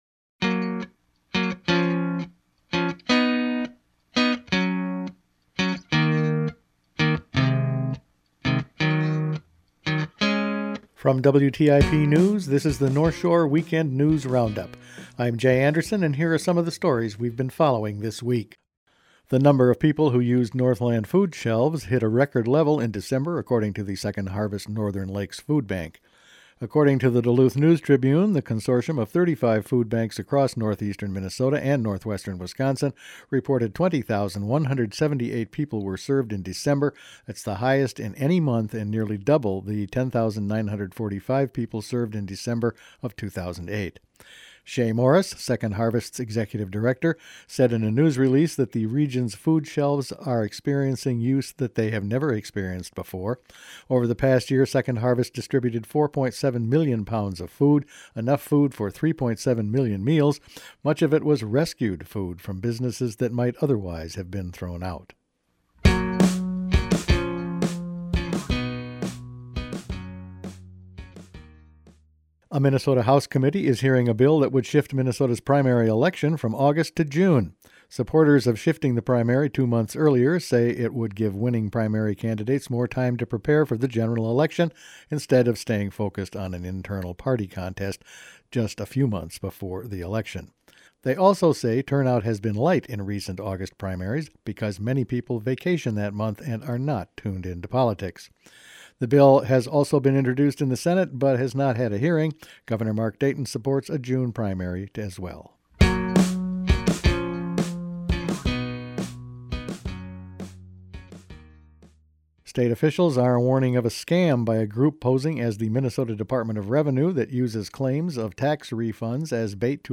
Weekend News Roundup for March 2